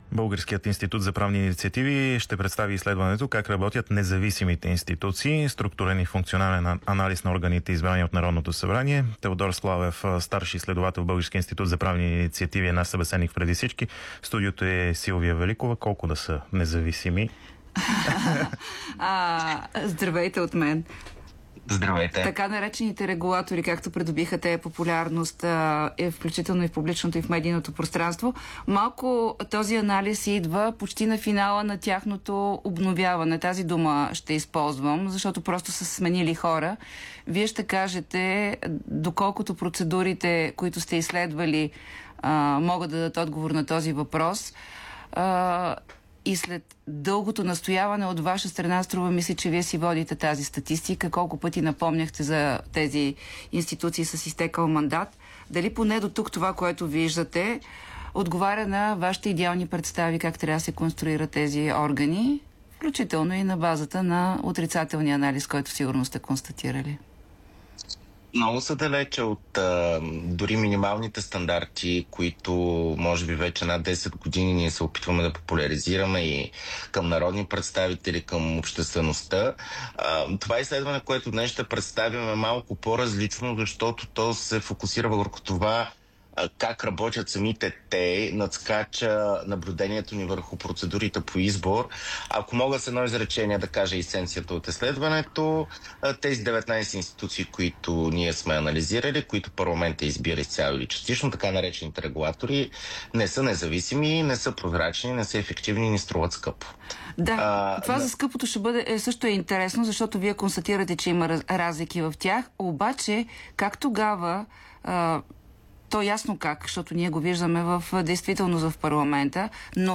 Слушайте цялото интервю тук Българският институт за правни инициативи ще представи изследването как работят независимите институции, структурен и функционален анализ на органите, извадени от Народното събрание.